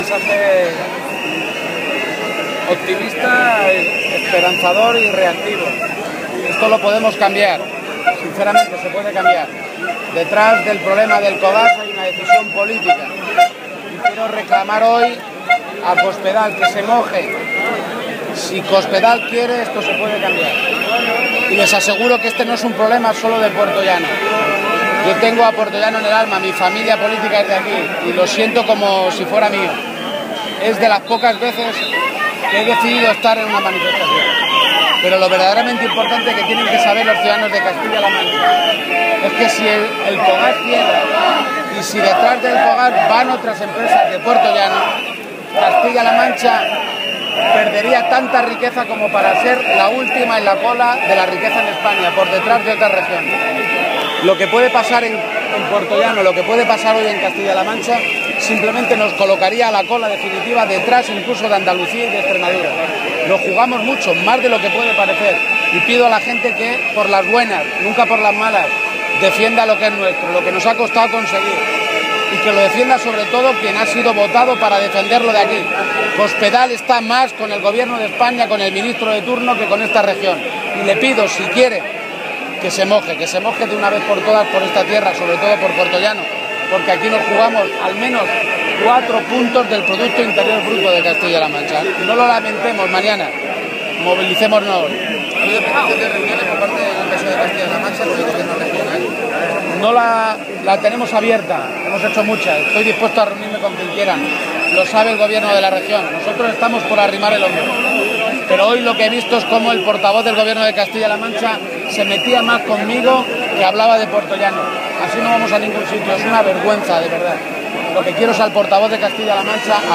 García-Page junto a la alcaldesa de Puertollano atendiendo a los periodistas